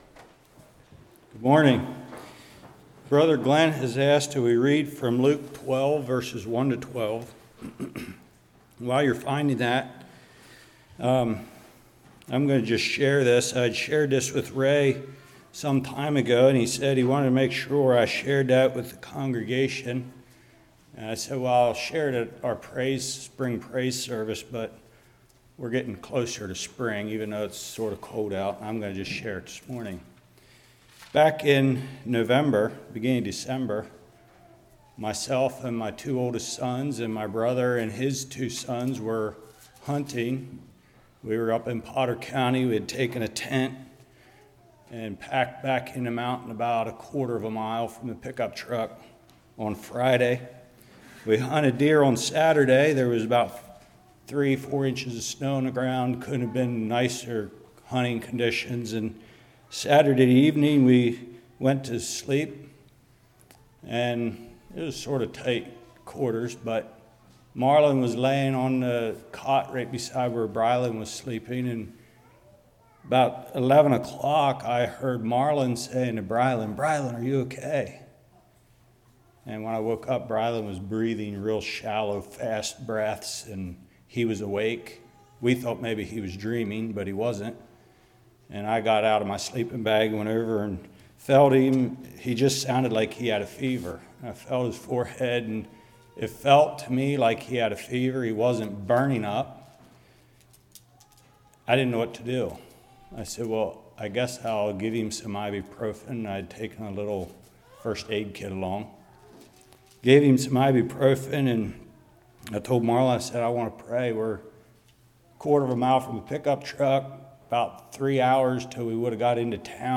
Luke 12:1-12 Service Type: Morning Be not afraid of them that kill the body.